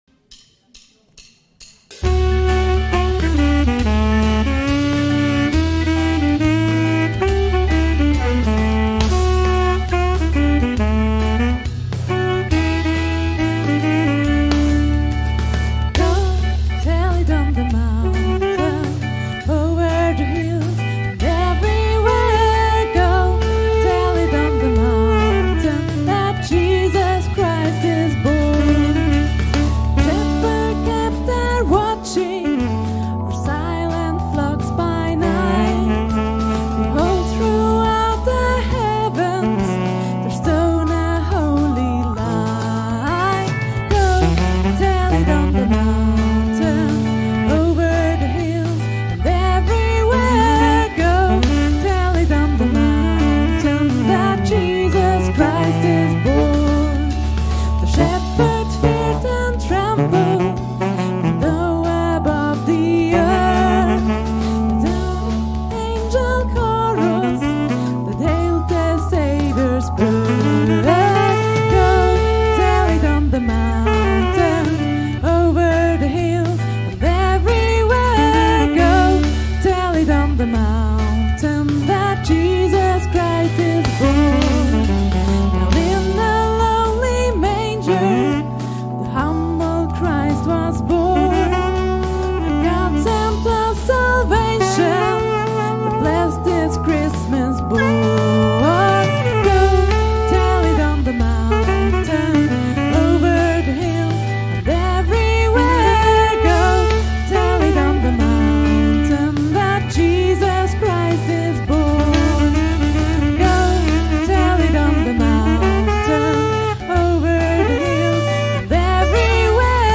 Weihnachts-Gottesdienst 2020